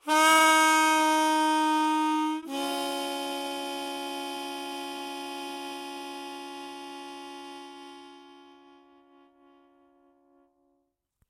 混合型标准口琴（所有调）点击+持续 " C口琴1 - 声音 - 淘声网 - 免费音效素材资源|视频游戏配乐下载
口琴用我的AKG C214在我的楼梯上录制的单声道，以获得那种橡木的音色